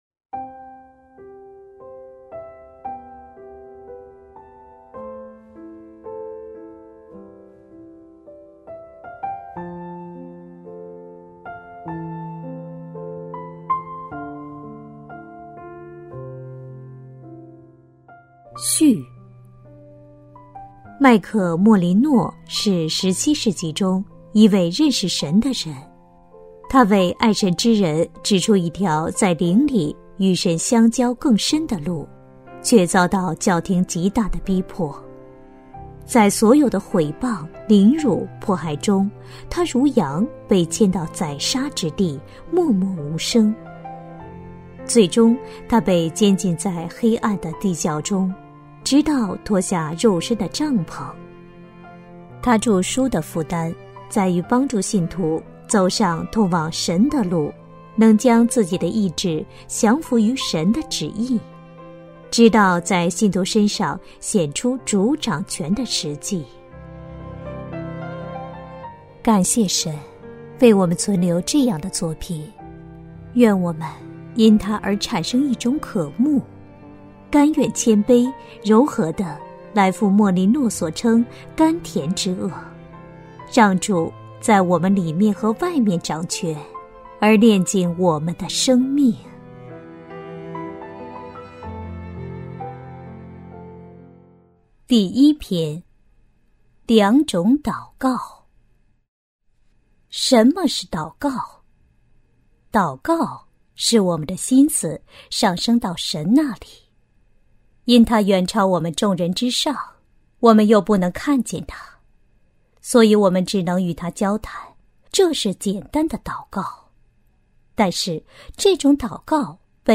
首页 > 有声书 | 灵性生活 | 灵程指引 > 灵程指引 第一篇：序、两种祷告